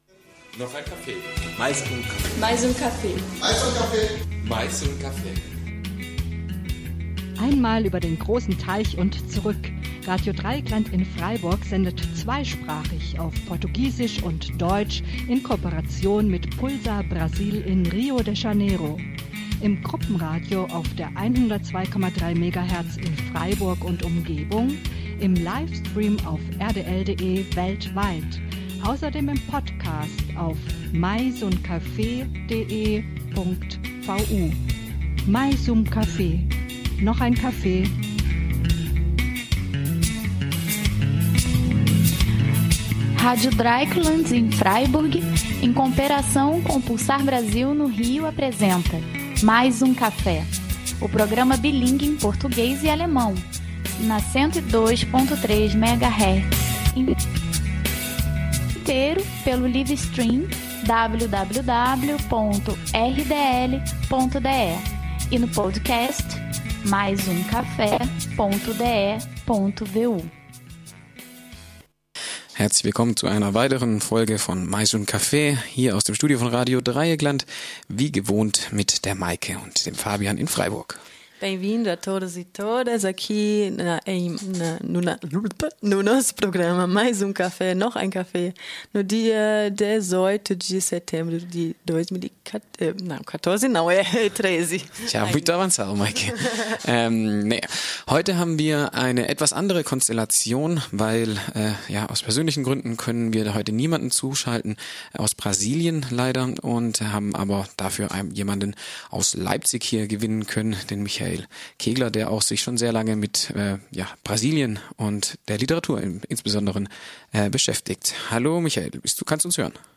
29.08.2013Ende August haben wir eine live Sendung direkt vom AMARC Seminar in Belém, Pará für euch. Thema der Sendung ist das Menschenrecht auf Kommunikation in Brasilien.